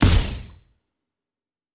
pgs/Assets/Audio/Sci-Fi Sounds/Weapons/Sci Fi Explosion 20.wav at master
Sci Fi Explosion 20.wav